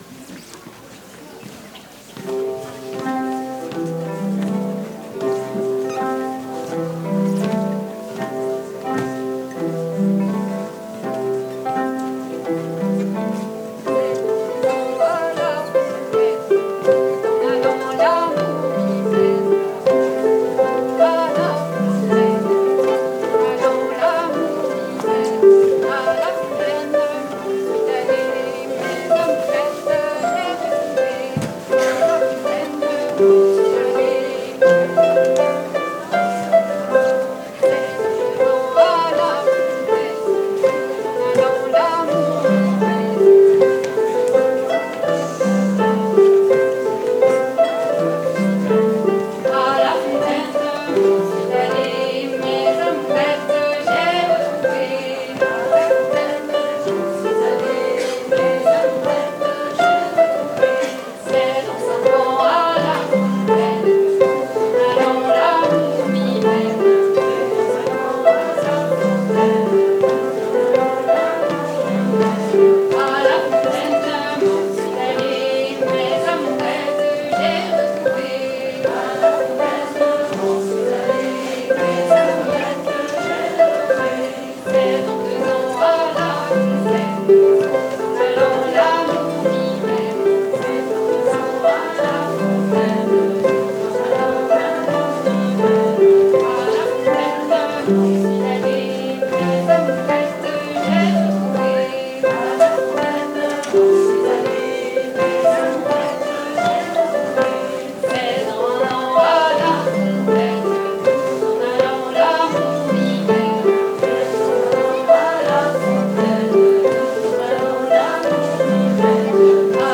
06_tours-harpes.mp3